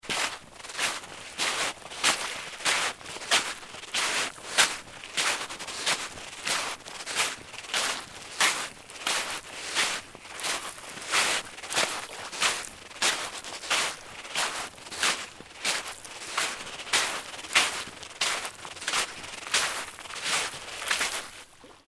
Person Walking On The Beach ringtone free download
Sound Effects